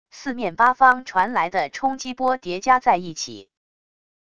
四面八方传来的冲击波叠加在一起wav音频